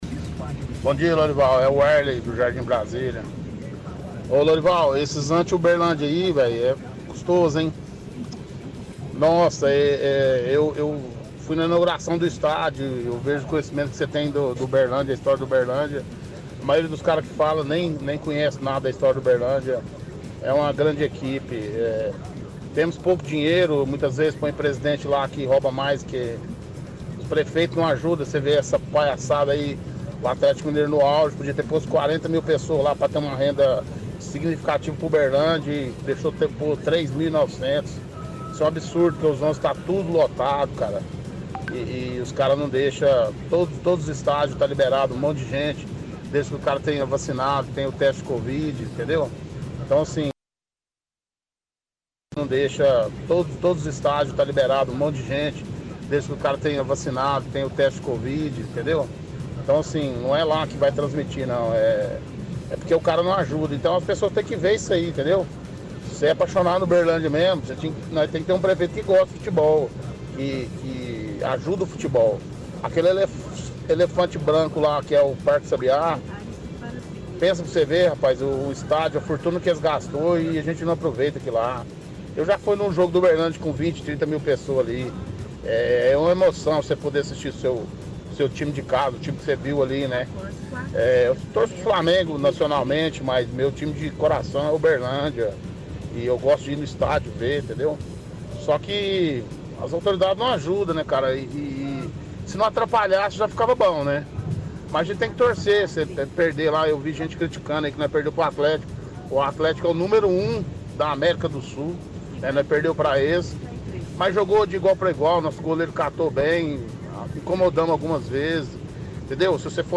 – Ouvinte reclama que não tem permissão de público no estádio como em outras cidades.